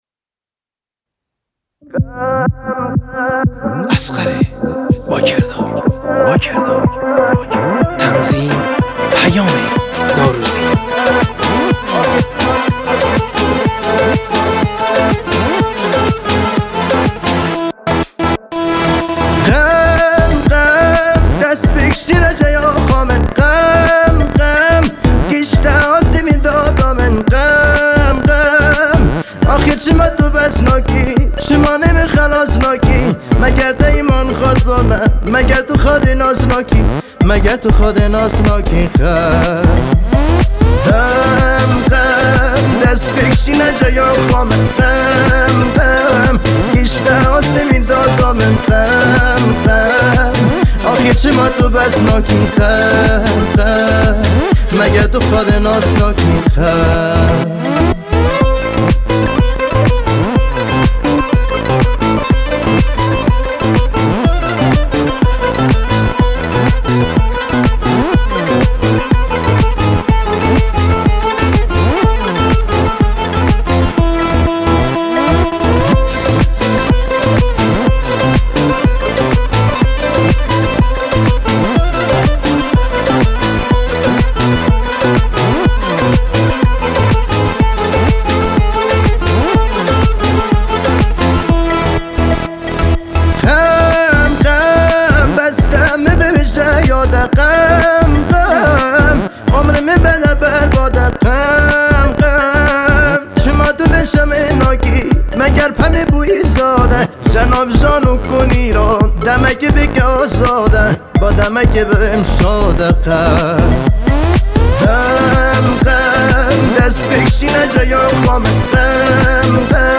آهنگ کرمانجی
دانلود اهنگ های شاد کرمانجی
موسیقی شاد محلی